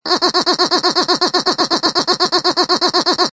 Descarga de Sonidos mp3 Gratis: risa 16.